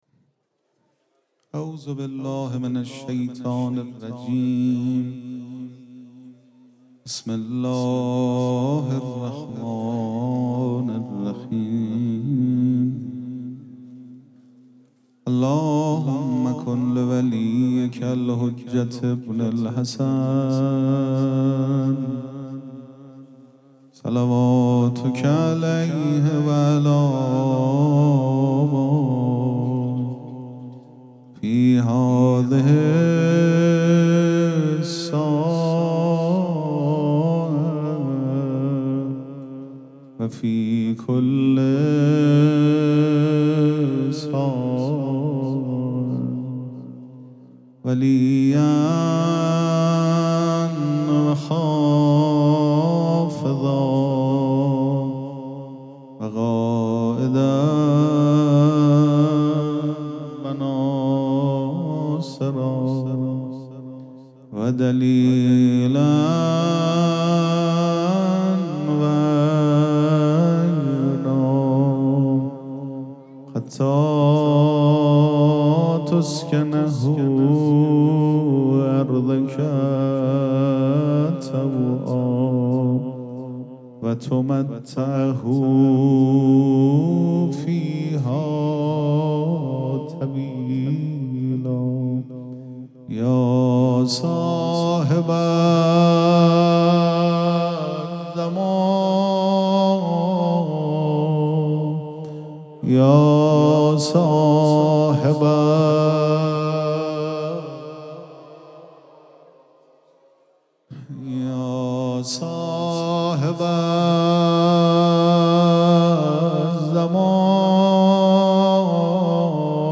ذکر توسل
مراسم جشن ولادت حضرت زینب سلام‌الله‌علیها
پیش منبر